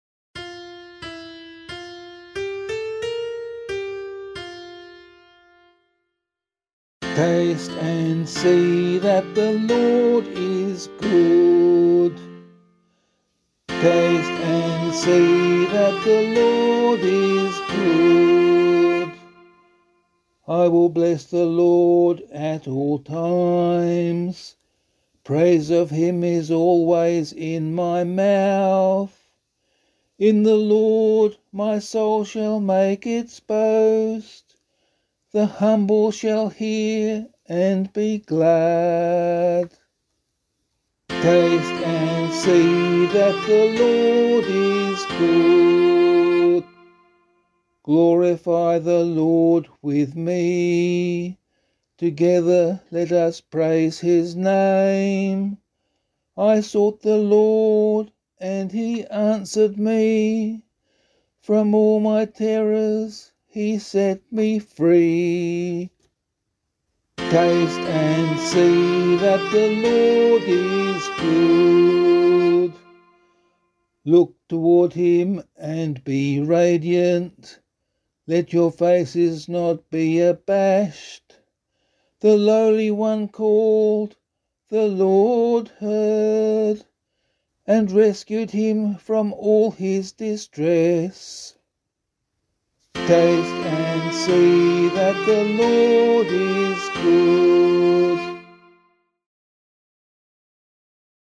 016 Lent 4 Psalm C [APC - LiturgyShare + Meinrad 6] - vocal.mp3